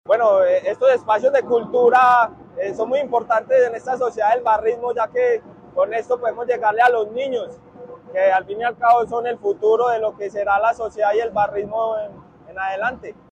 Barrista Deportivo Pereira.